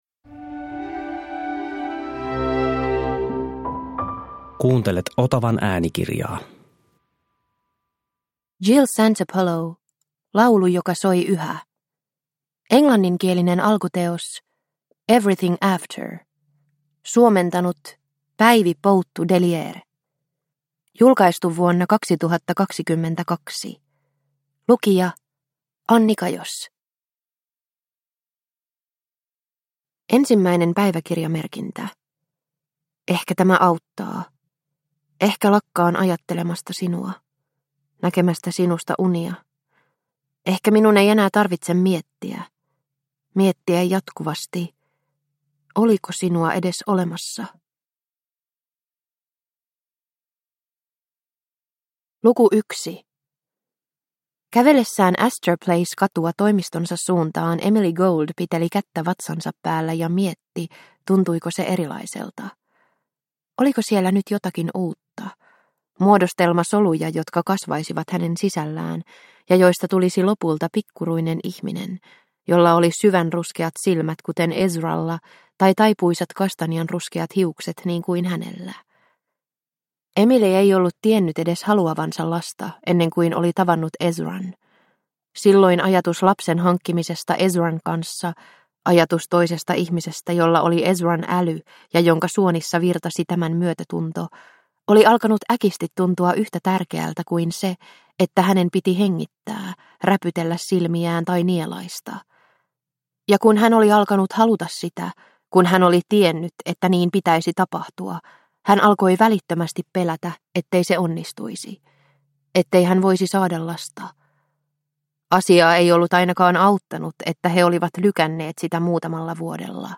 Laulu joka soi yhä – Ljudbok – Laddas ner